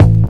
Closed Hats
Hat (60).wav